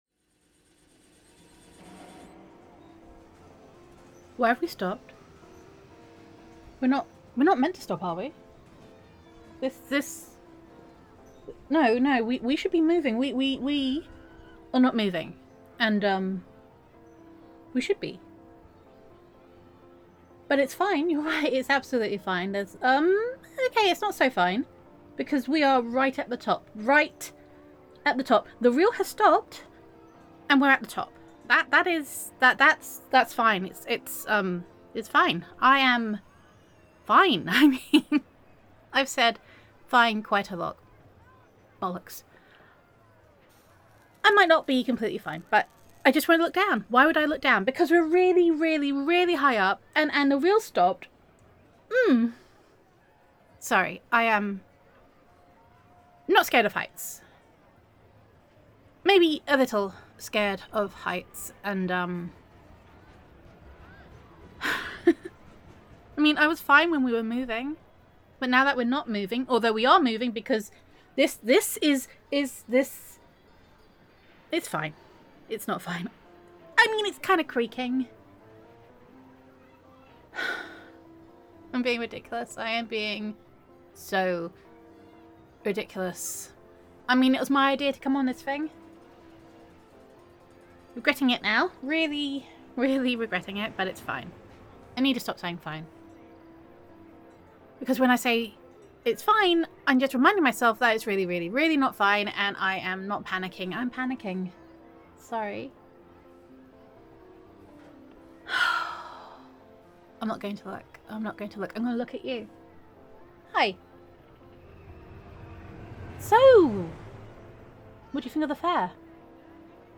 [F4A] Stuck With You [Best Friend Roleplay]